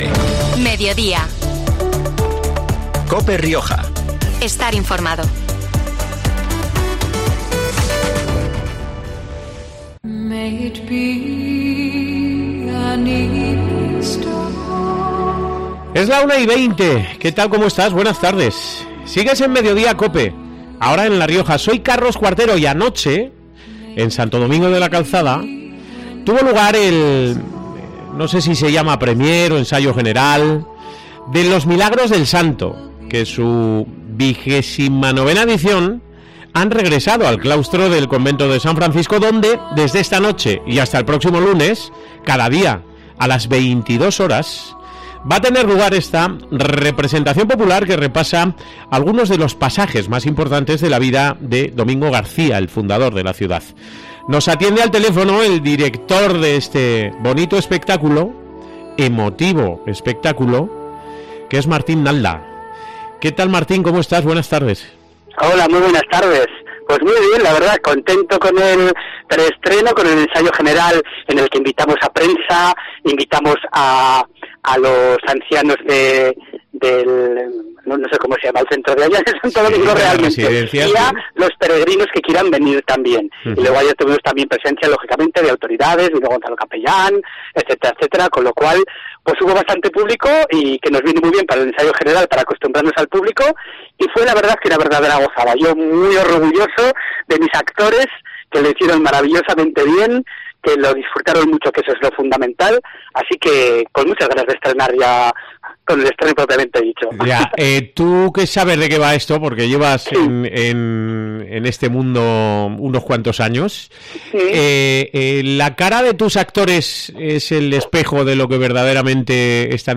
este viernes ha pasado por los micrófonos de COPE Rioja para valorar el hecho que supone regresar al mismo escenario donde tuvieron lugar las primeras representaciones